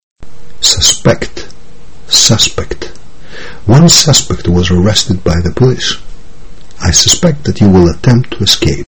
Προσοχή όμως στον διαφορετικό τονισμό του ρήματος απ’ αυτόν του ουσιαστικού/επιθέτου